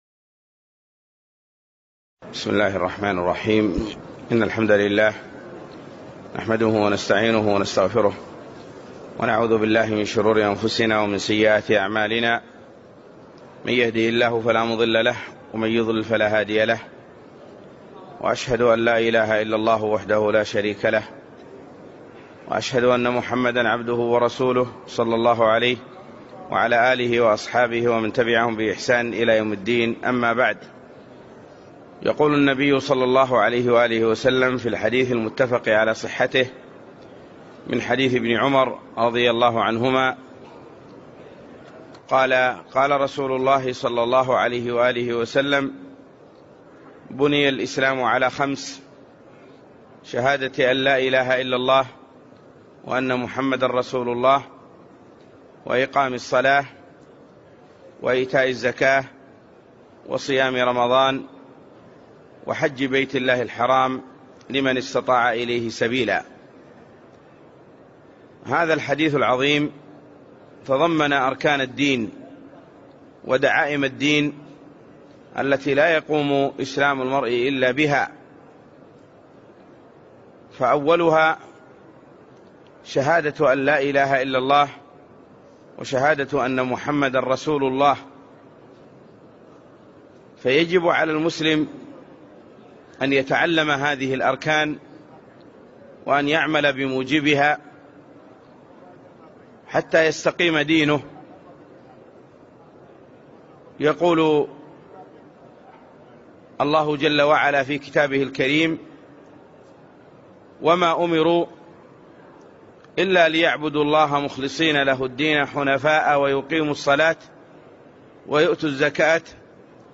محاضرة - أركان الإسلام الخمسة